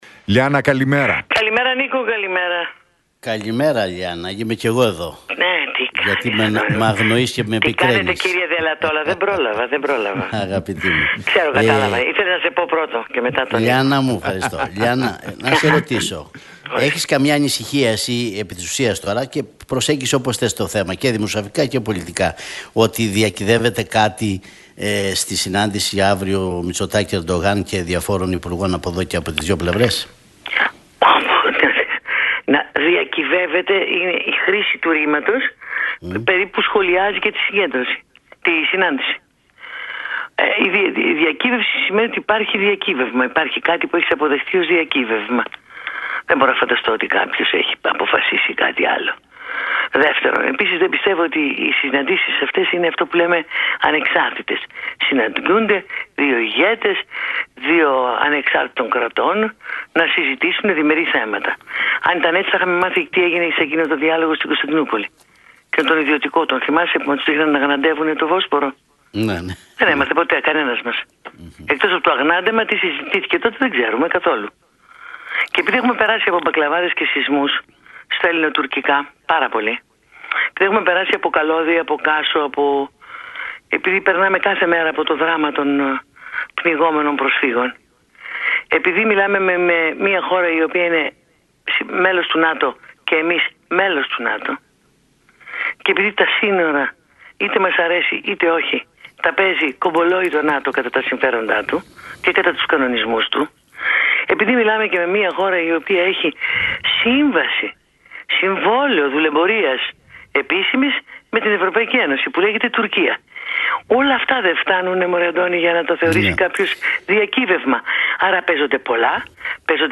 μιλώντας στο ραδιοφωνικό σταθμό Realfm 97,8